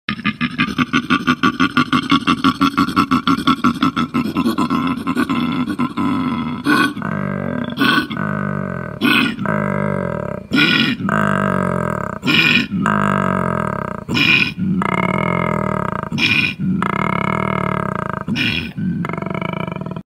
Son sobre todo los machos quienes usan los sonidos para marcar el territorio, y las hembras durante la cópula. No sé si es un bramido, berrido, casi rebuzno… pero sin duda sorprende.
koala-bear-sound.mp3